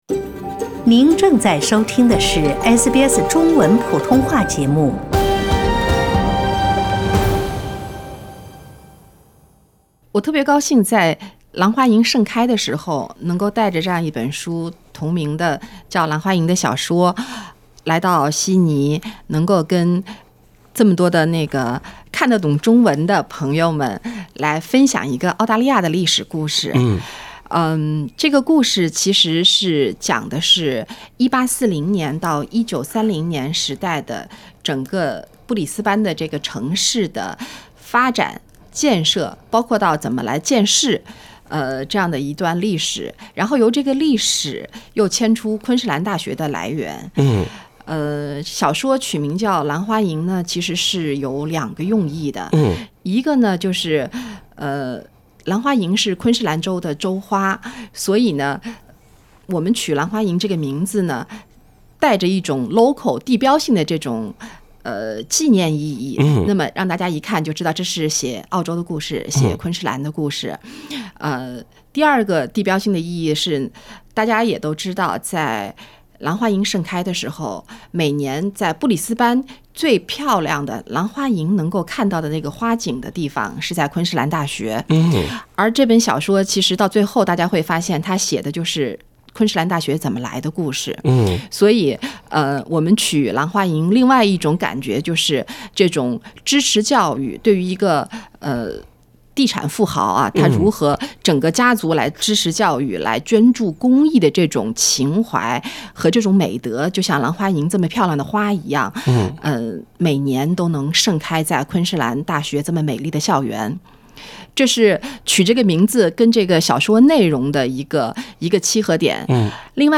点击文首图片收听完整采访。